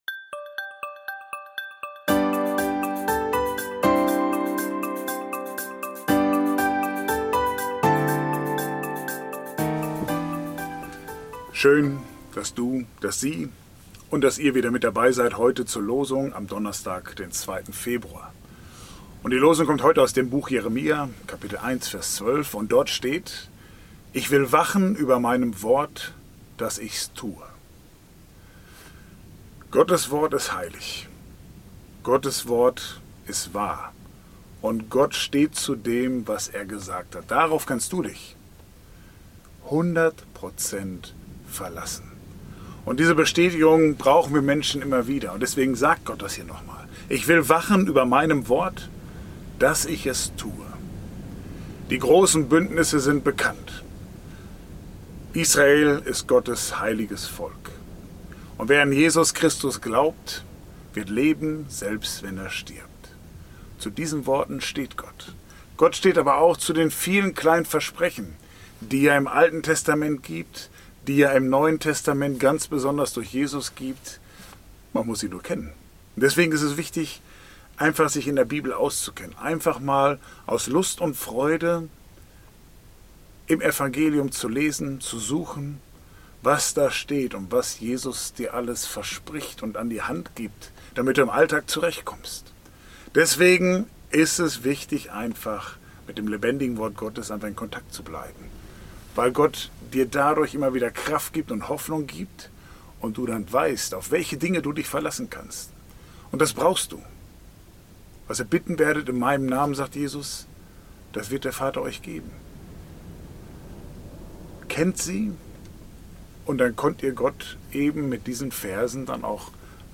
Religion & Spiritualität